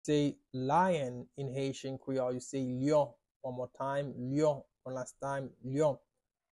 “Lion” in Haitian Creole – “Lyon” pronunciation by a native Haitian Creole teacher
“Lyon” Pronunciation in Haitian Creole by a native Haitian can be heard in the audio here or in the video below:
How-to-say-Lion-in-Haitian-Creole-–-Lyon-pronunciation-by-a-native-Haitian-Creole-teacher.mp3